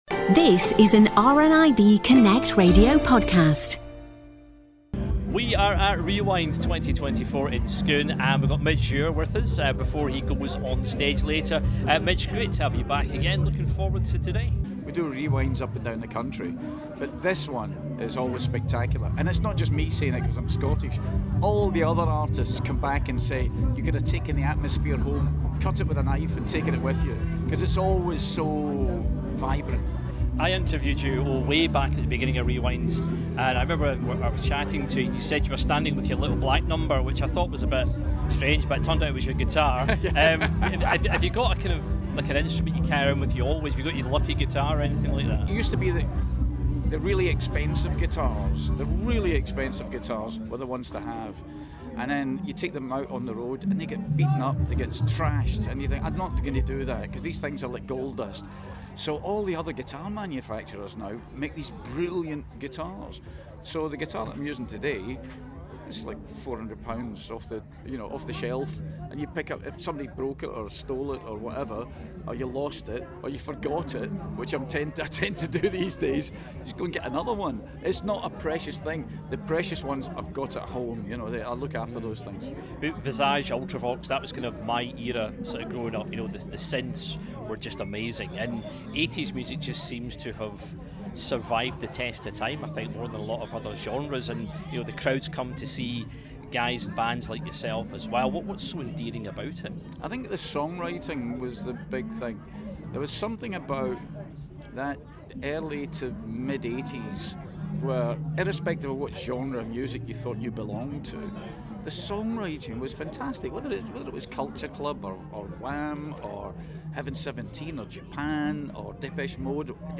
Rewind Scotland 2024 returned to the spectacular grounds of Scone Palace in Perth on Friday 19th till Sunday 21st of July to celebrate music icons of the 80s and beyond.
spoke with Scottish musician, singer-songwriter and record producer Midge Ure